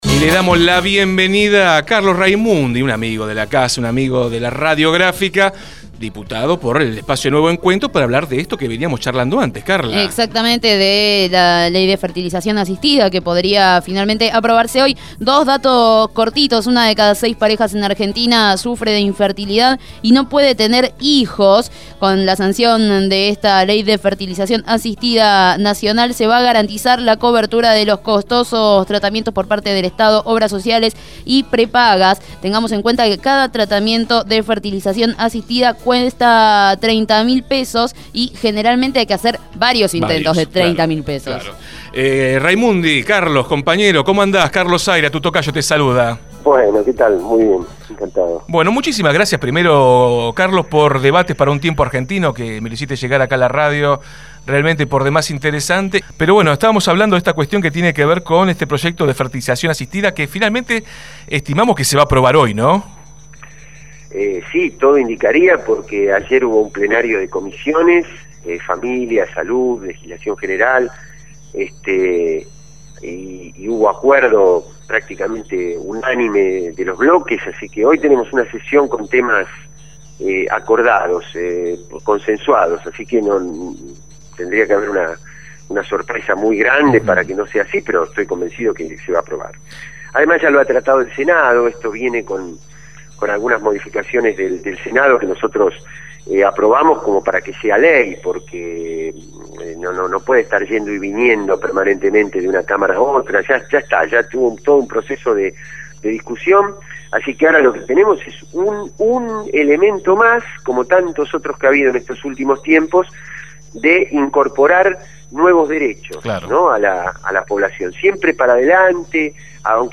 raimundiCarlos Raimundi, Diputado por Nuevo Encuentro, fue entrevistado en Desde el Barrio en relación al debate sobre la Ley Nacional de Fertilización Asistida.